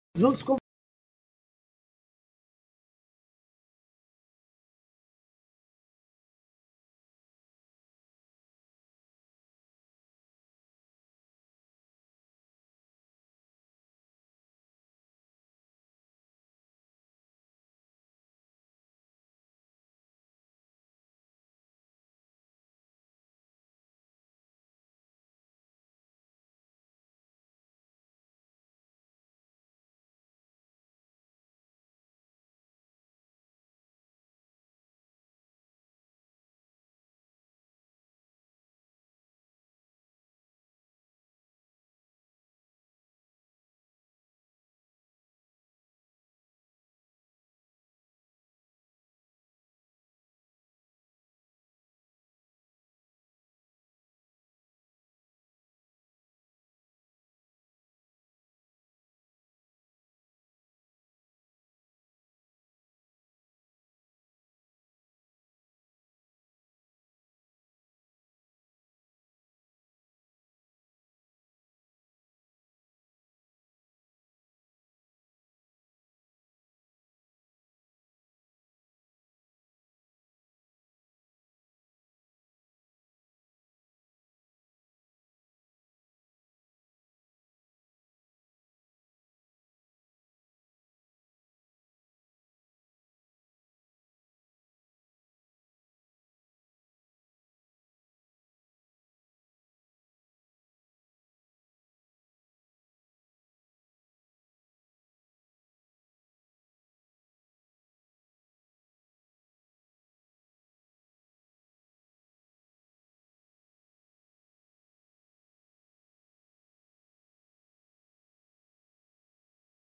O programa Excelsior Saúde, acontece das 9h às 10h, ao vivo com transmissão pela Rádio Excelsior AM 840.